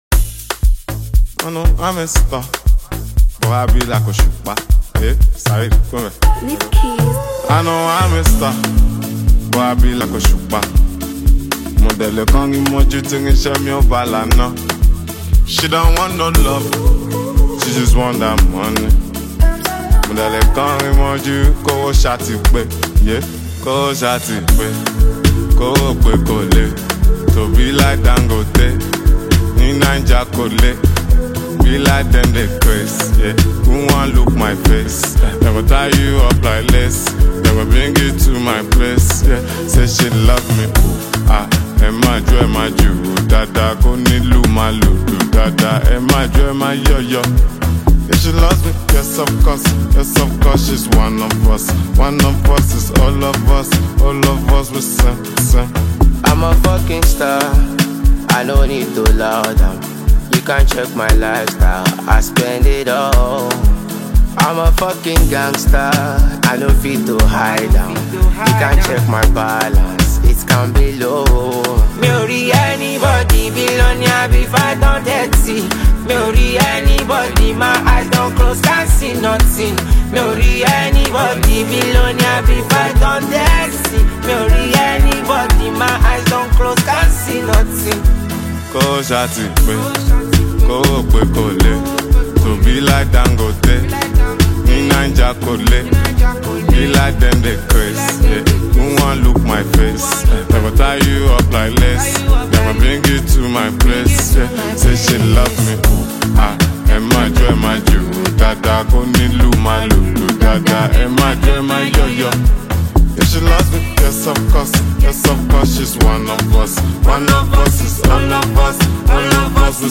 it showcases his unique style and infectious energy.
With its catchy beat and memorable lyrics
Afrobeat genre